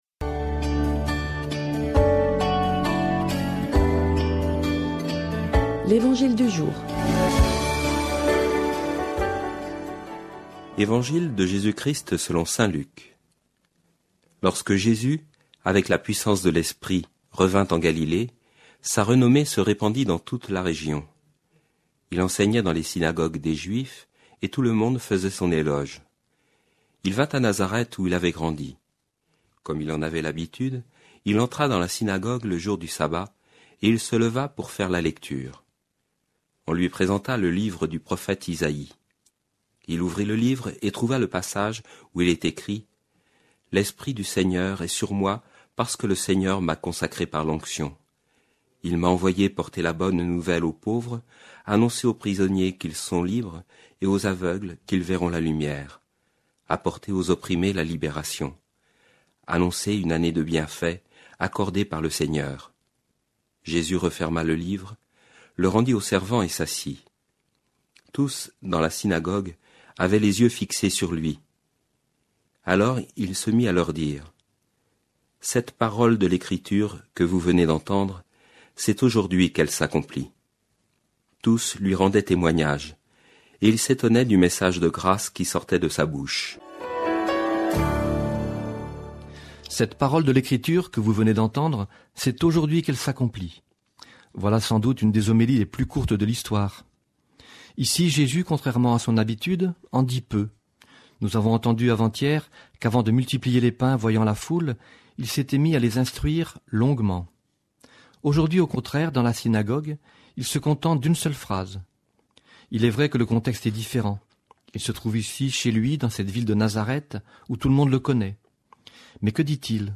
Commentaire radiophonique enregistré pour Fidélité, Nantes.